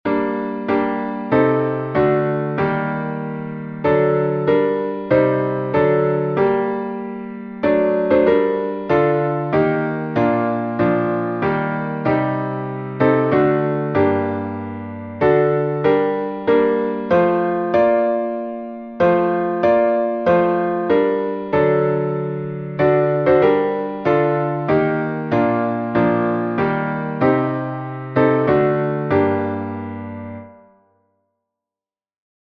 salmo_73B_instrumental.mp3